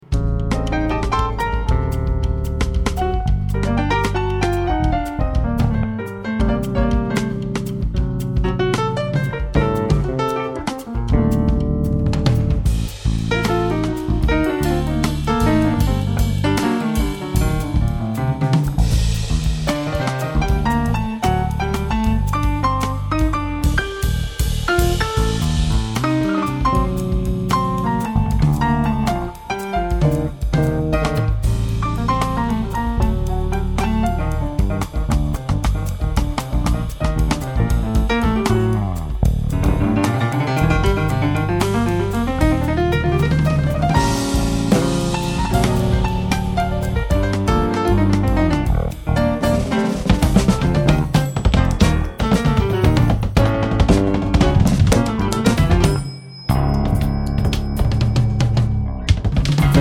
Batería & Percusión